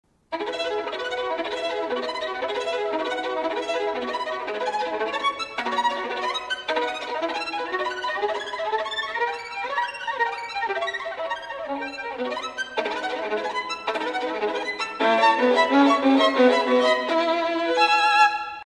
قسمتی از اجرای چهار سیمه در کاپریس شماره یک پاگانینی را بشنوید